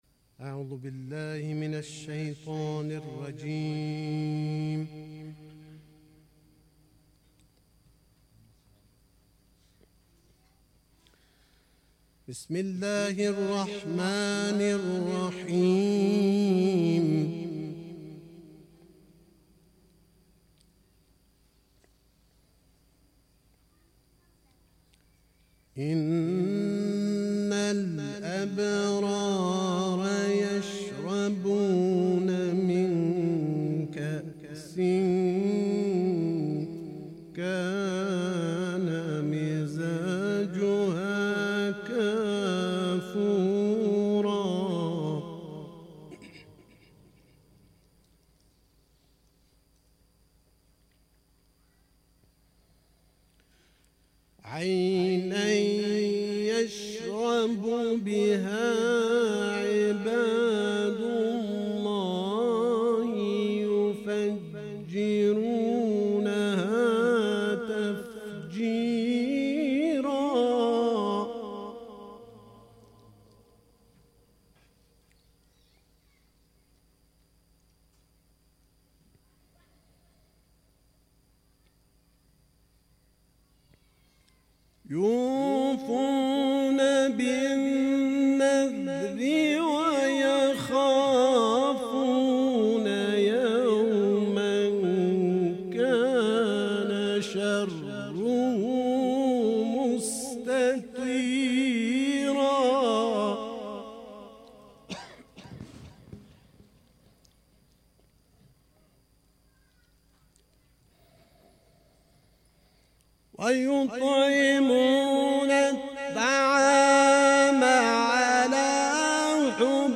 محرم 97